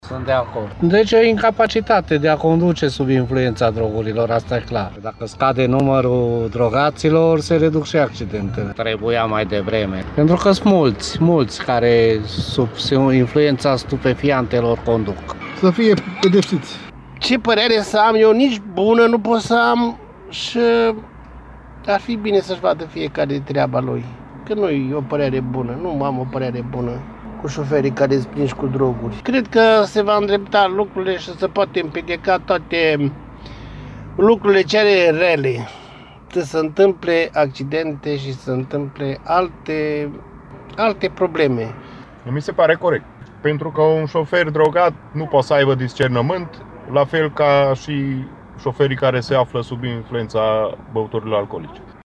Şoferii mureşeni spun că înăsprirea pedepselor ar fi binevenită: